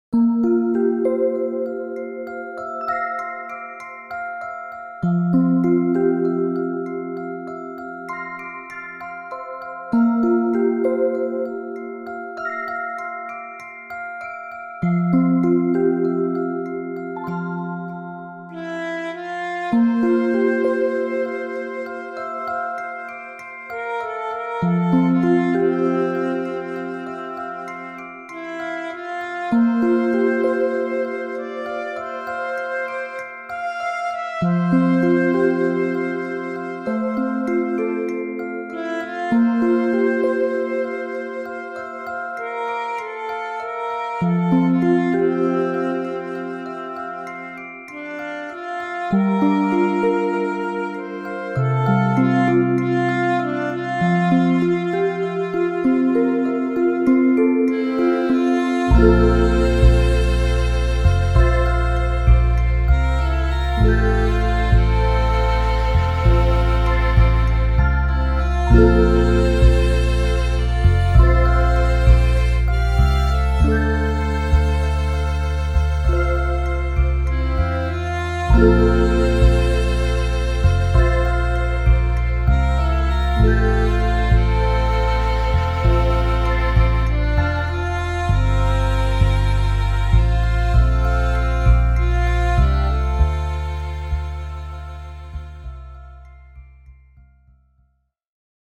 アレンジ
妙に小綺麗にまとめようとしてるのが気に入らないのでボツ